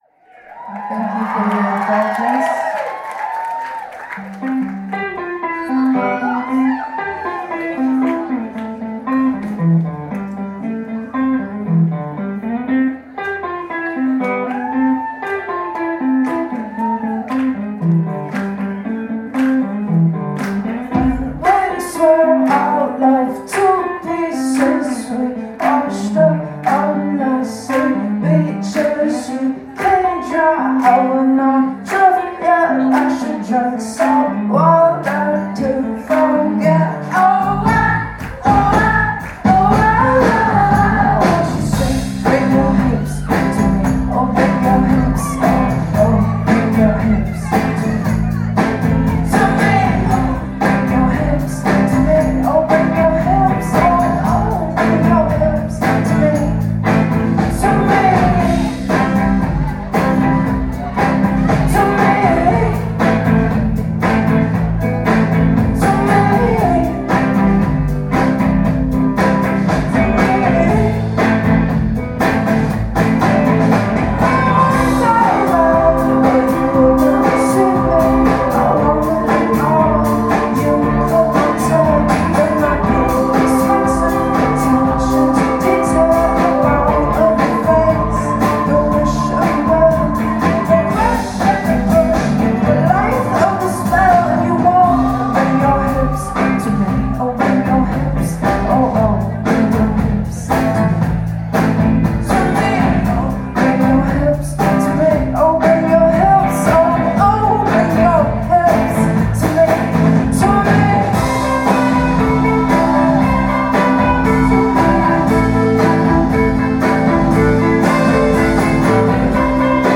Great idea, great show.
I can get behind the more rockin’ sound.